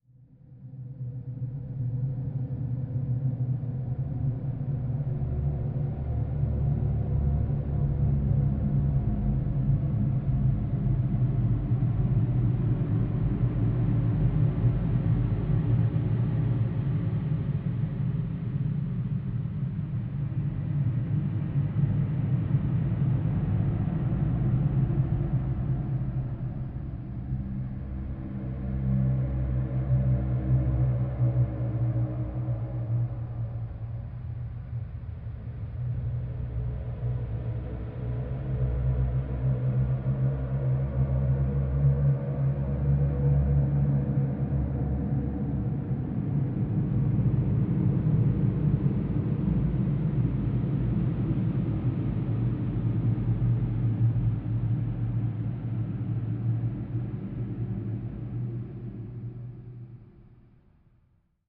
Звук прогнившей плесени